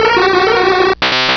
pokeemerald / sound / direct_sound_samples / cries / drowzee.aif
-Replaced the Gen. 1 to 3 cries with BW2 rips.